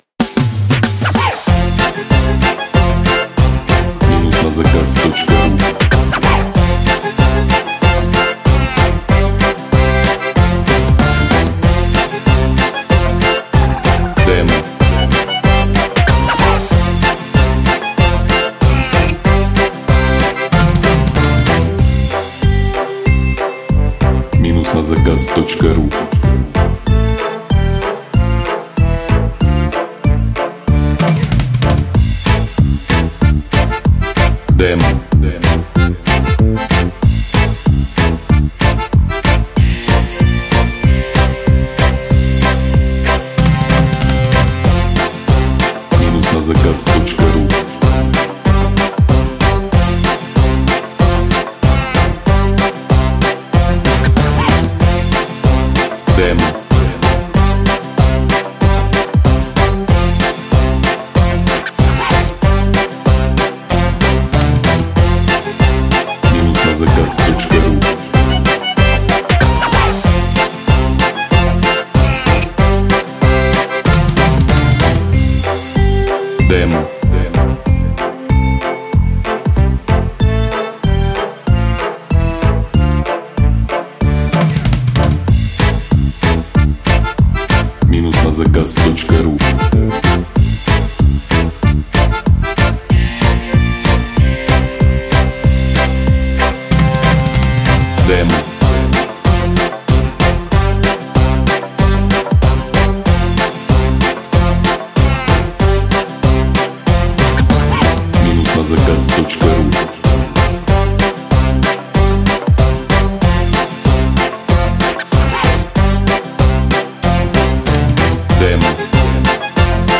Главная » Файлы » Демо минусовок